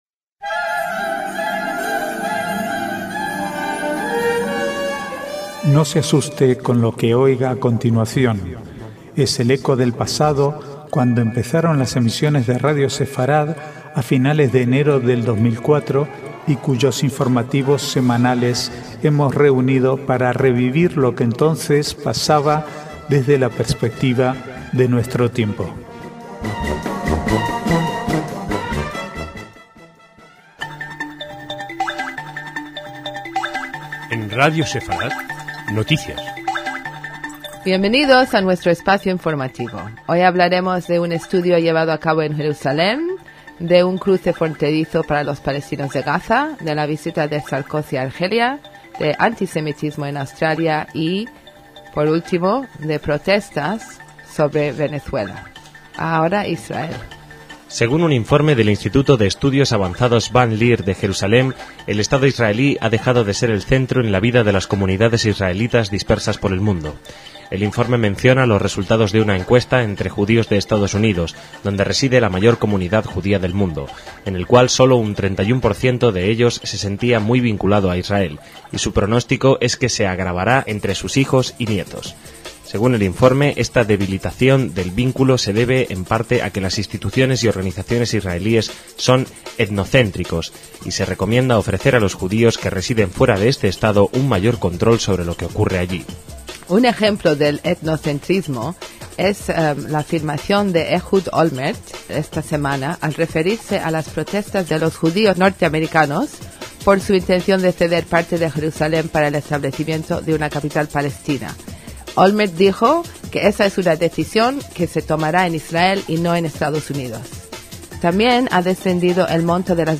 informativos semanales